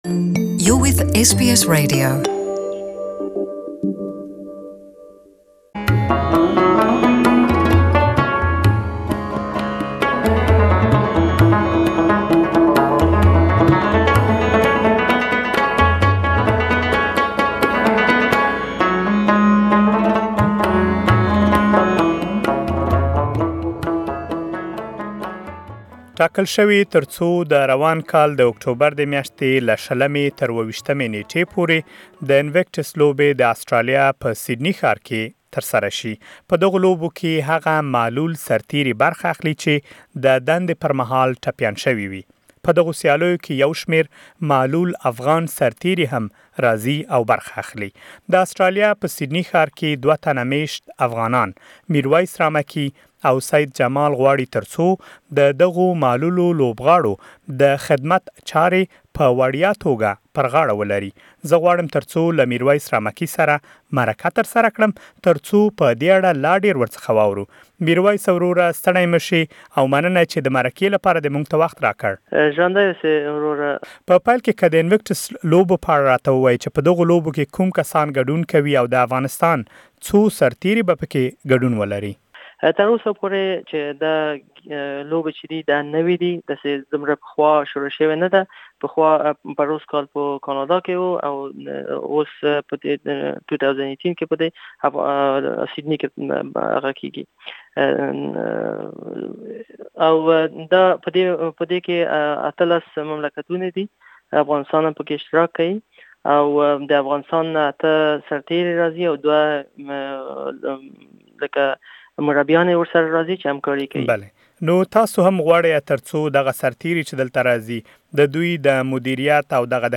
As the wounded warriors from Afghanistan are also participating in the Invictus Games, SBS Pashto has conducted an interview with one of the volunteers who wants to assist and care for the wounded Afghan players while they are in Sydney. Please, listen to the interview here.